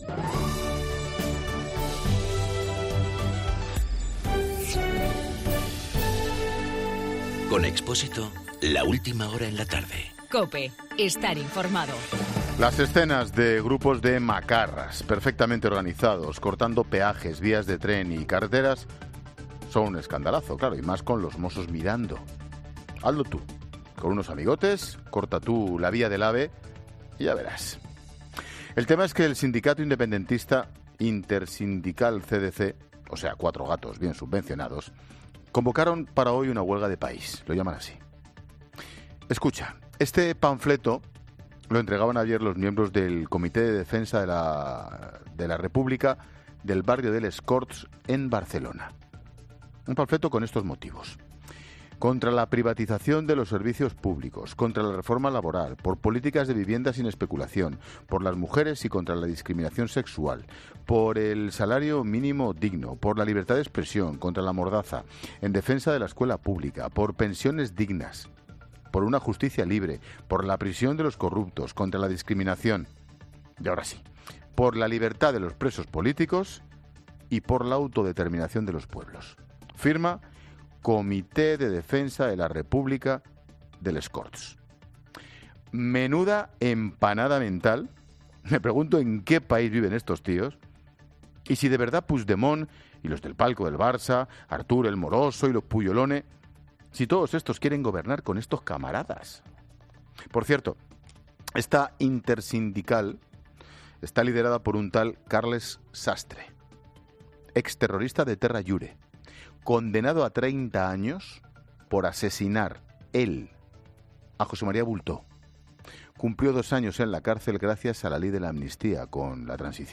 AUDIO: El comentario de Ángel Expósito sobre la huelga general en Cataluña.